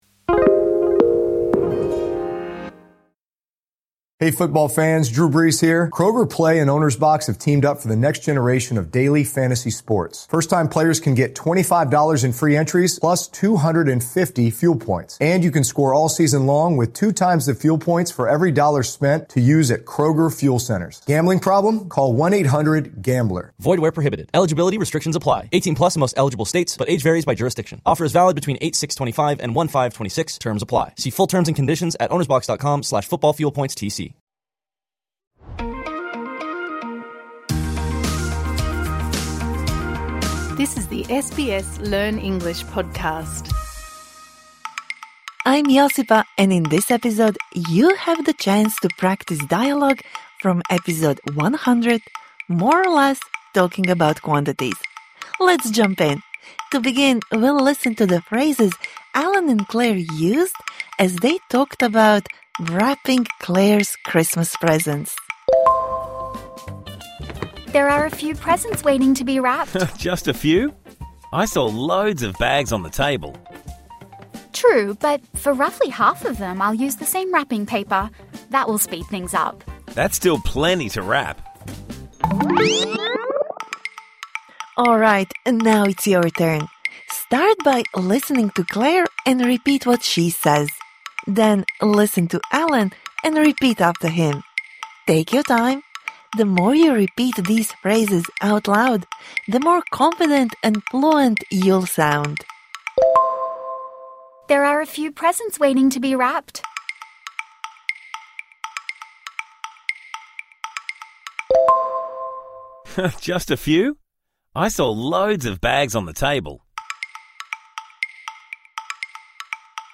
Practise speaking the dialogue from episode #100 More or less? Talking about quantities